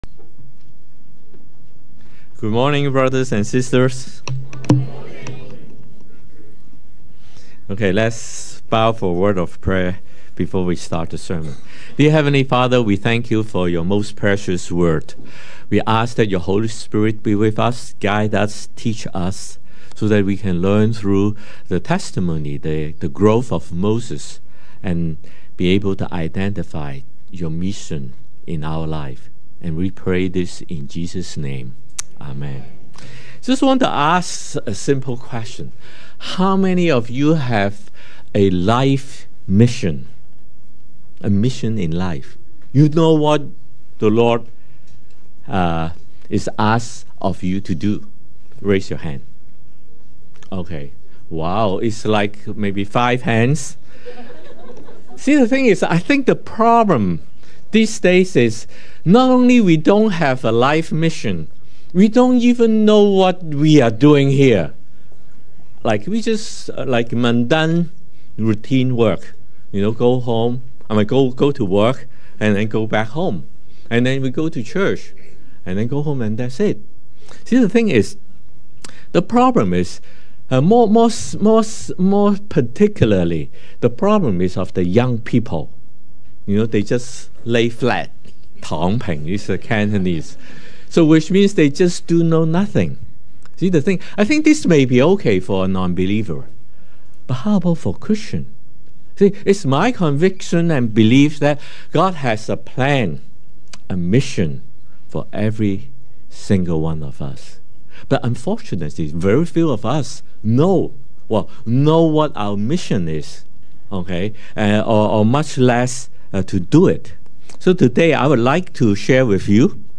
English Worship (LCK) - Mission Identification Through Growth